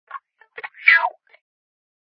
misc_funkGuit00.mp3